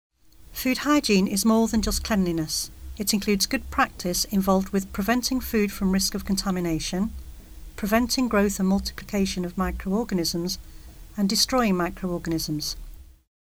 Narration audio (MP3) Narration audio (OGG) Select each image below to find out about the three topics listed.